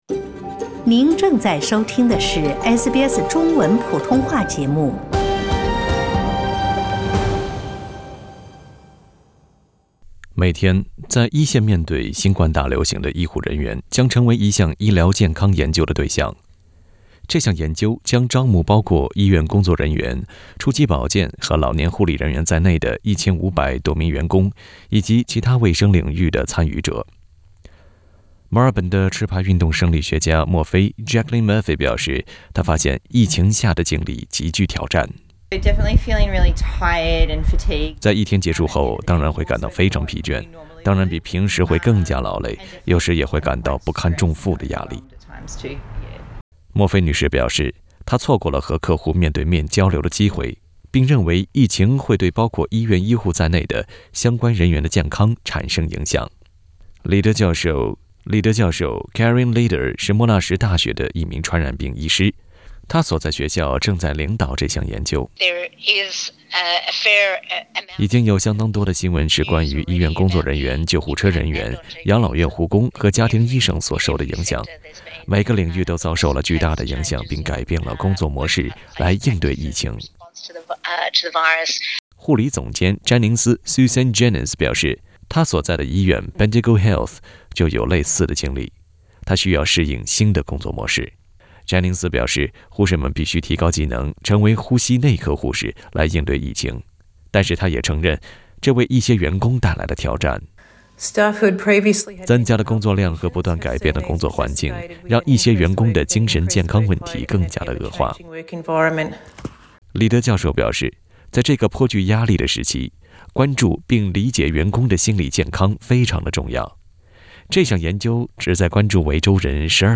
（點擊圖片音頻，收聽報道）